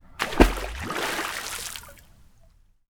Water_14.wav